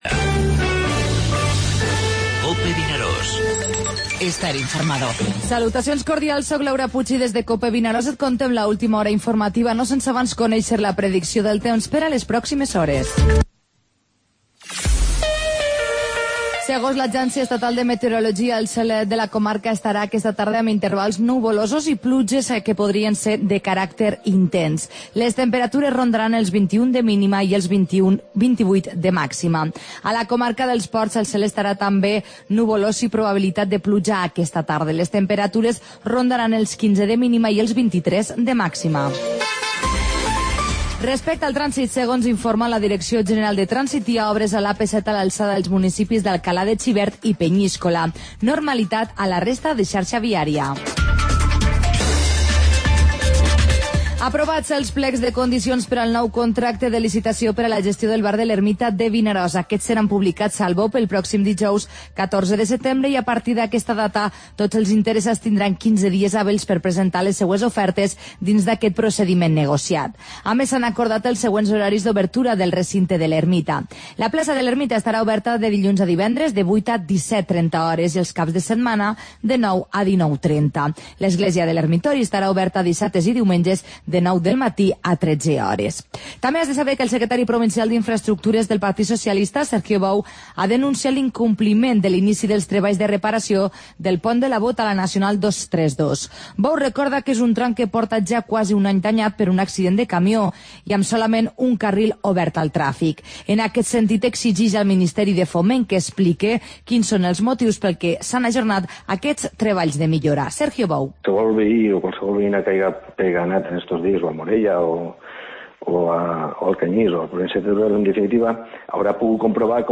Informativo Mediodía COPE al Maestrat (dimarts 13 de setembre)